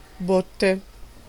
Ääntäminen
IPA : /kɑːsk/